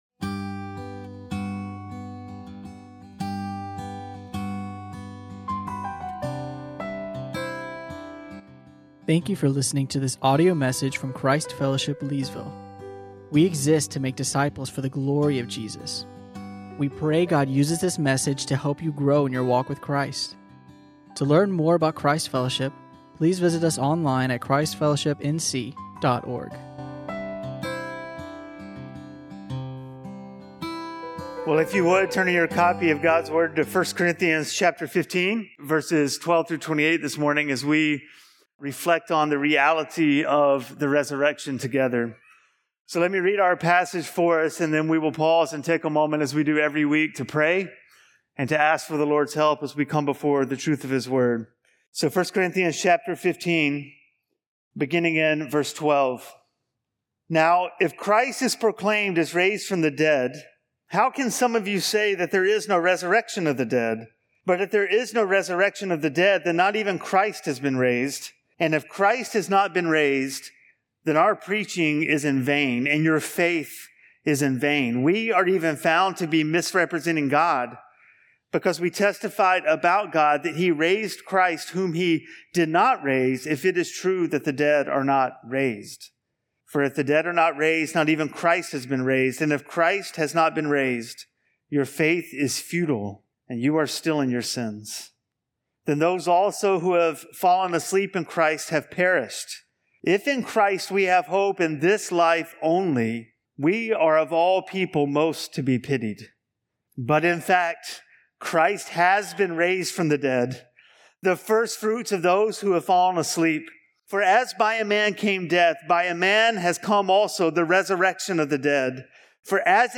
1 Corinthians 15:12-28 (Easter Sunday)